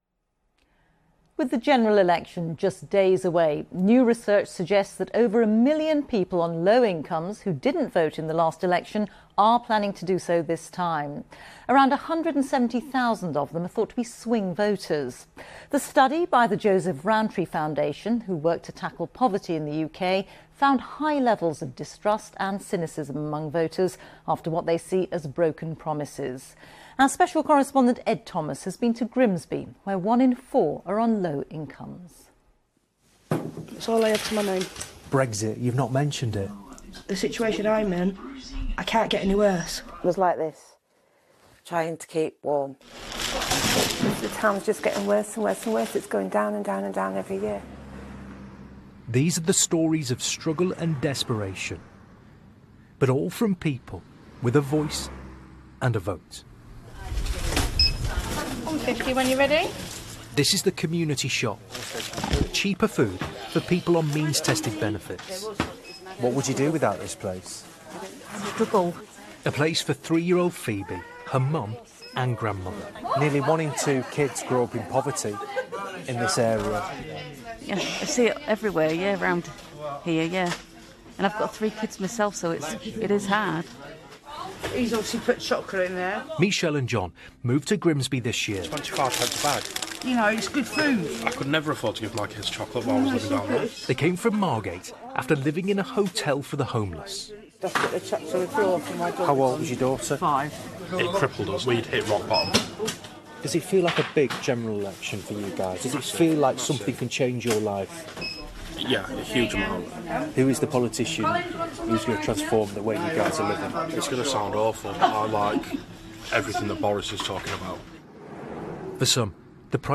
BBC news dec 4 i.mp3